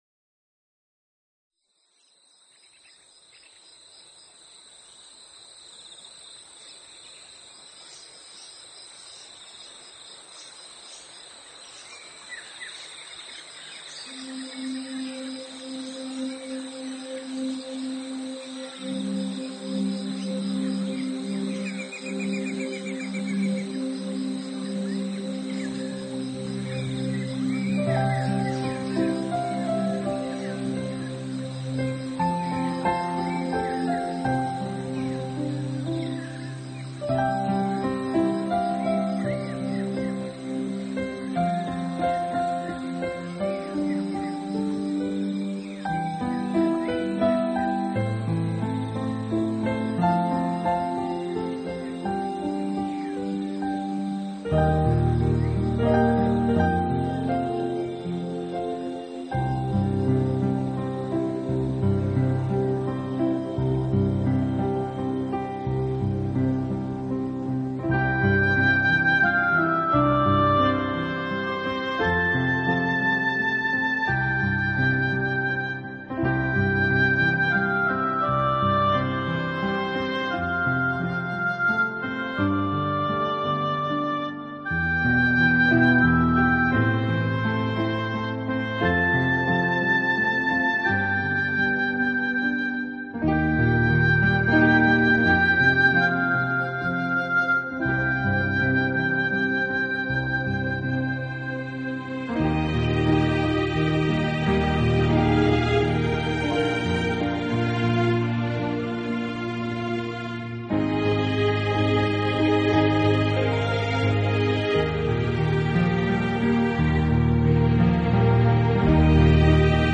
音乐类型：New Age